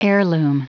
Prononciation du mot heirloom en anglais (fichier audio)
Prononciation du mot : heirloom